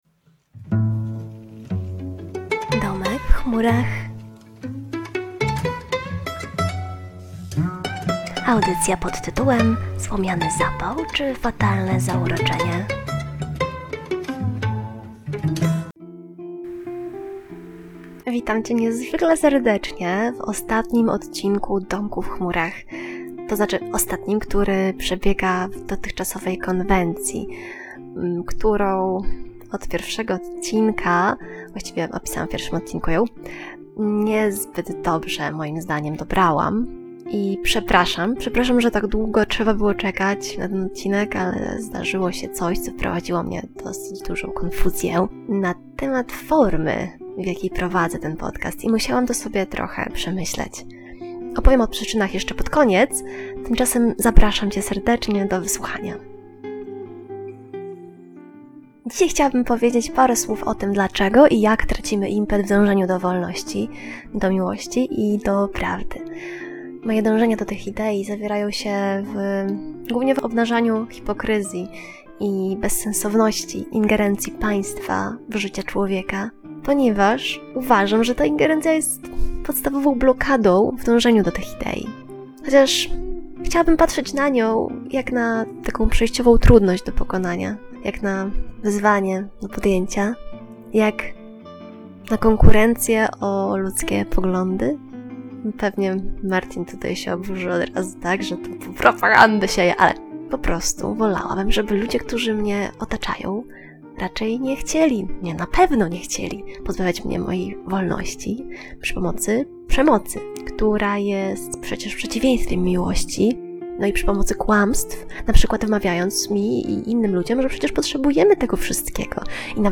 Audycja o ideach.